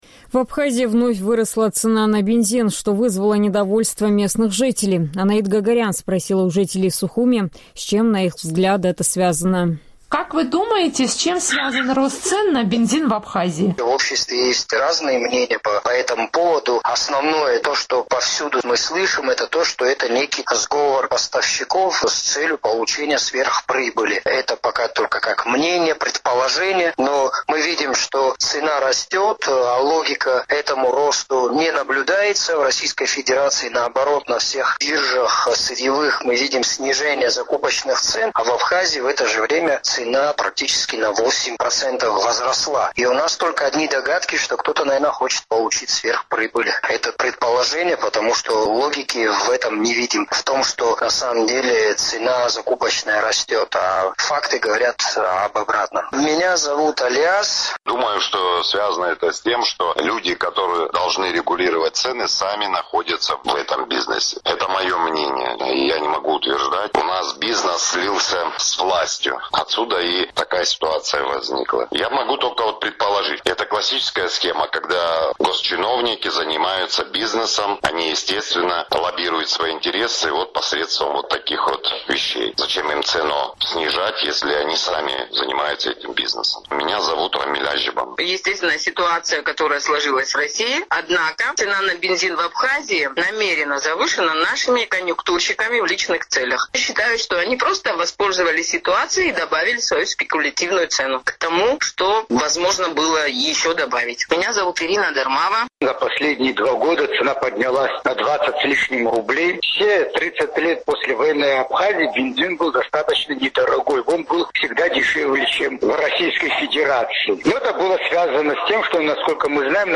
«Эхо Кавказа» спросило у жителей Сухума, с чем, на их взгляд, связан рост цен на ГСМ.